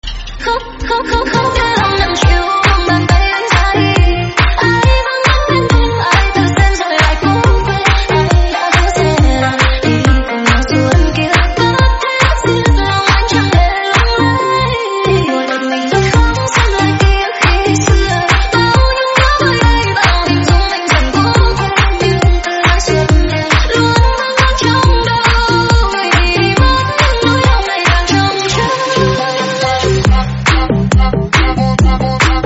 giai điệu remix sôi động đang hot 2025.